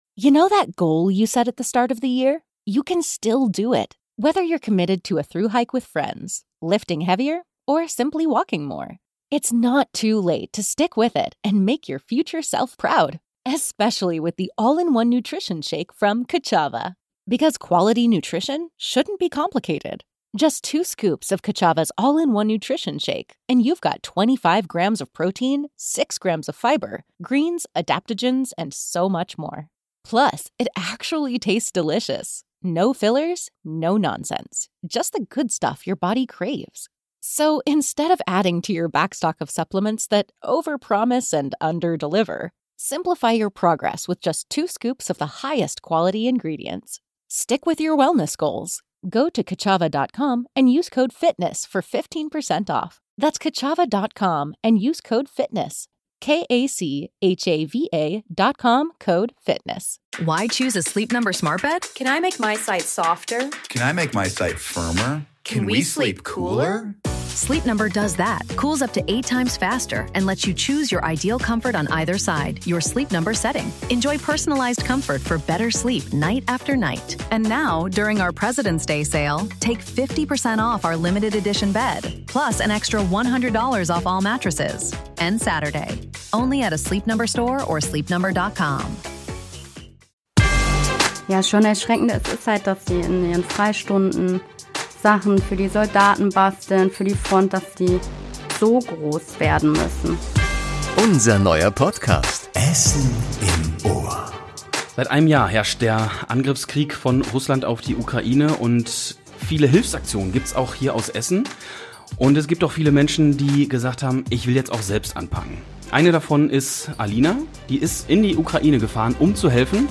#73 spezial - Essenerin hilft in der Ukraine ~ Essen im Ohr - Der Talk mit Persönlichkeiten aus der Stadt Podcast